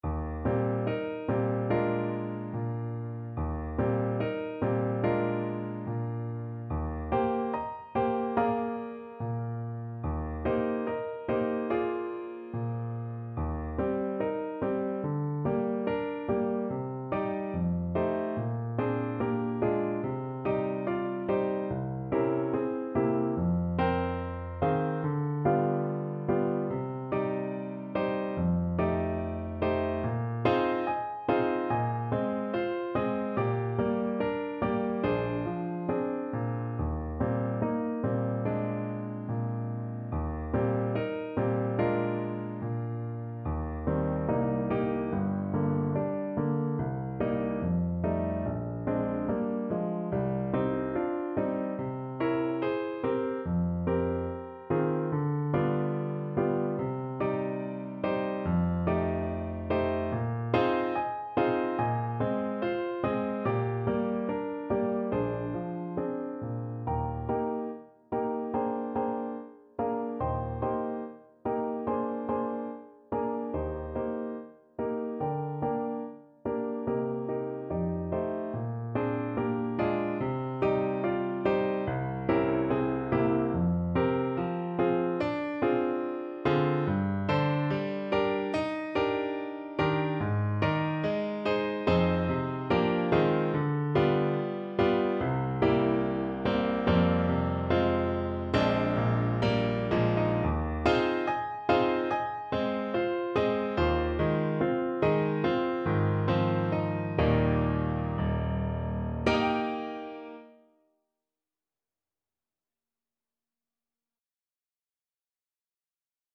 Play (or use space bar on your keyboard) Pause Music Playalong - Piano Accompaniment Playalong Band Accompaniment not yet available reset tempo print settings full screen
4/4 (View more 4/4 Music)
Eb major (Sounding Pitch) Bb major (French Horn in F) (View more Eb major Music for French Horn )
~ = 72 In moderate time